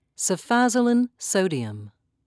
(sef-a'zoe-lin)